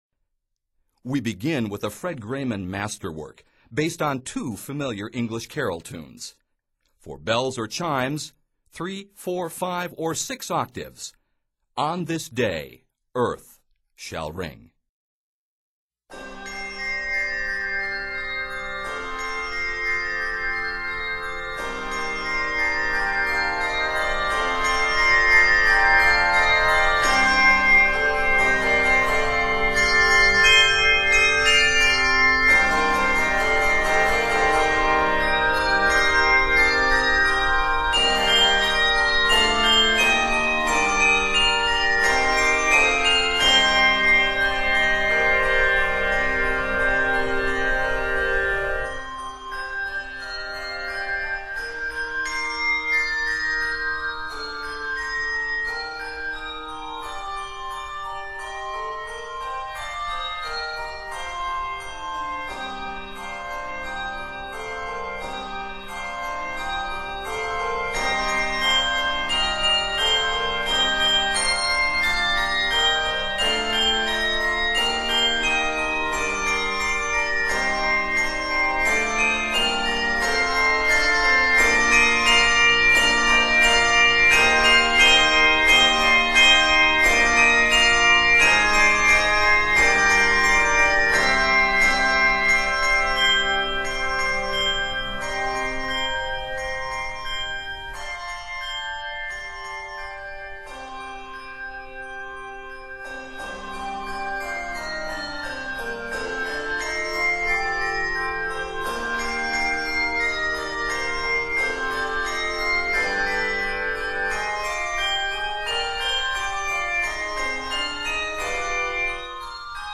It is arranged in c minor.